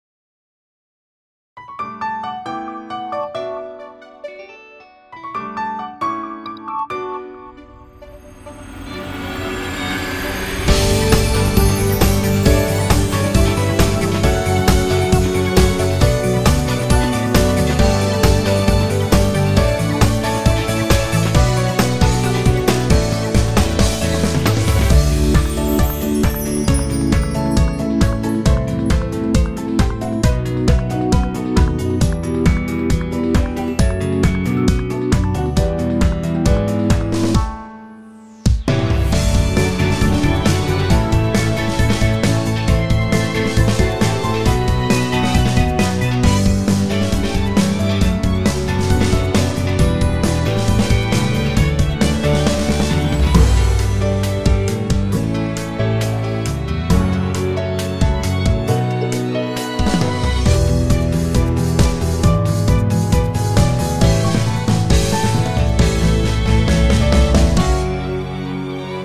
楽曲 ROCK＆POP